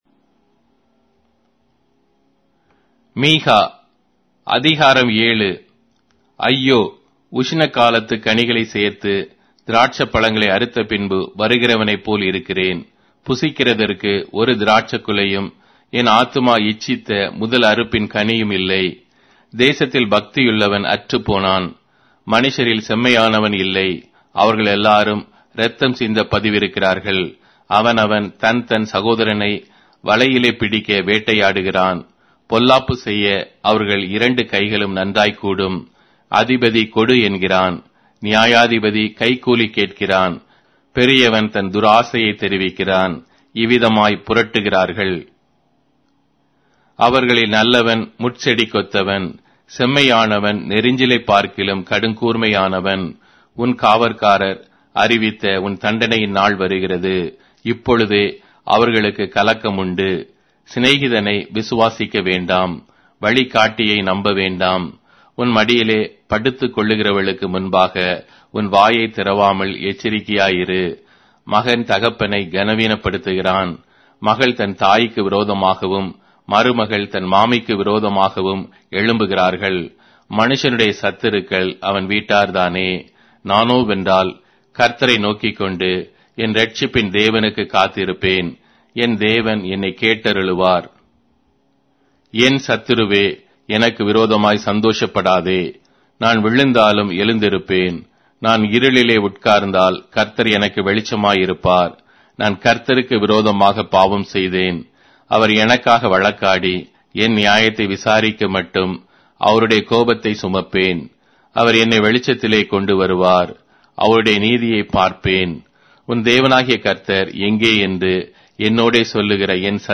Tamil Audio Bible - Micah 5 in Alep bible version